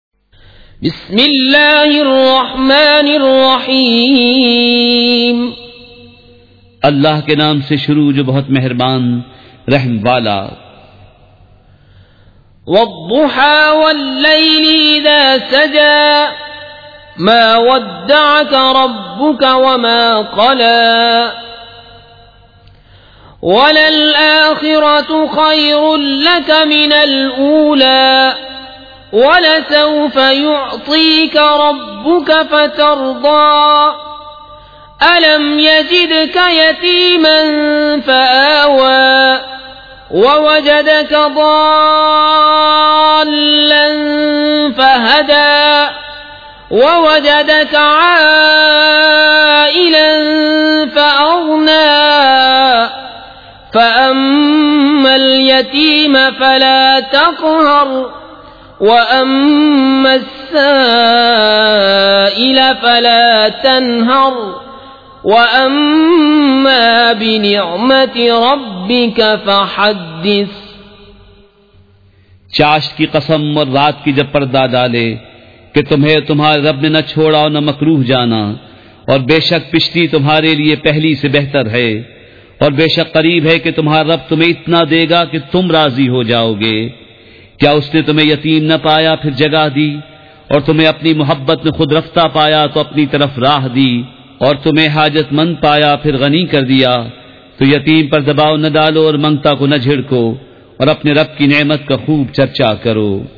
سورۃ الضّحی مع ترجمہ کنزالایمان ZiaeTaiba Audio میڈیا کی معلومات نام سورۃ الضّحی مع ترجمہ کنزالایمان موضوع تلاوت آواز دیگر زبان عربی کل نتائج 2479 قسم آڈیو ڈاؤن لوڈ MP 3 ڈاؤن لوڈ MP 4 متعلقہ تجویزوآراء